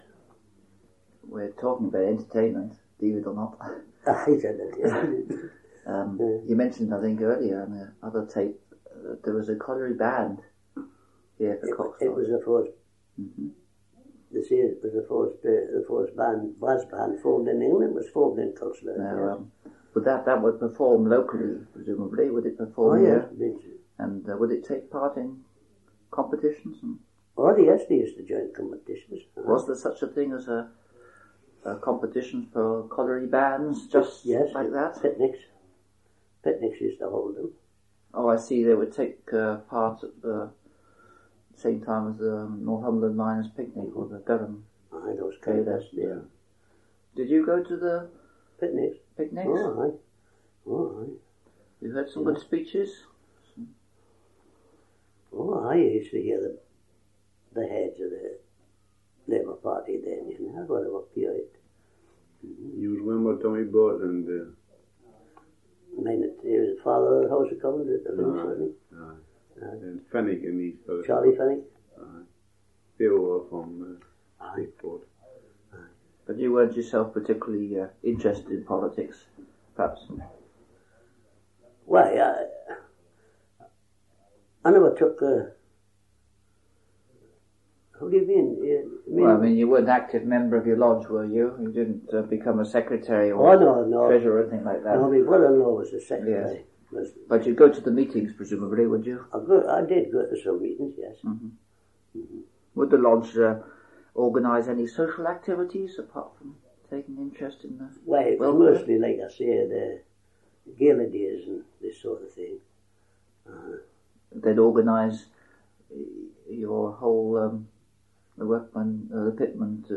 These sound files are extracts (short, edited pieces) from longer oral history interviews preserved by Northumberland Archives. These interviews were made as part of a project to collect the memories of people who lived and worked in Morpeth; “Memories of Morpeth,” 2011.
There is another voice on the recording that hasn’t been identified.